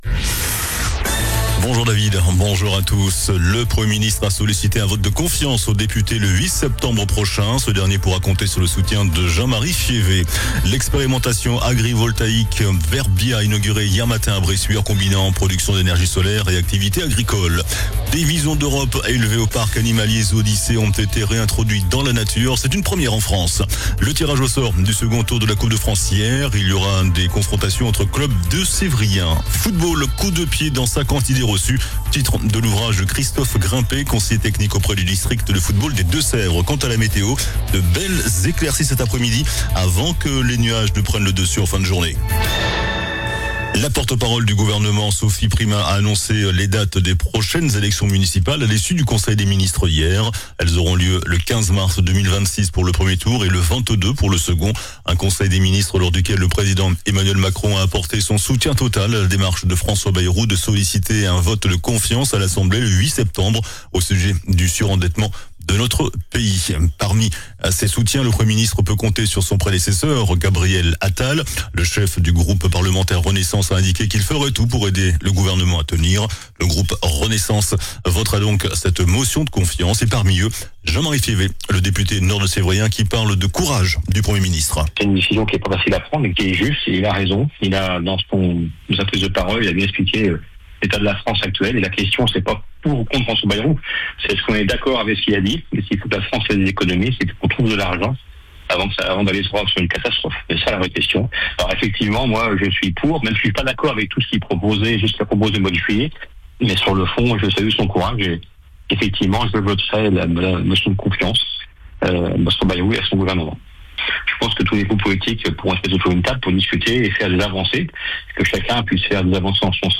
JOURNAL DU JEUDI 28 AOÛT ( MIDI )